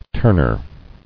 [turn·er]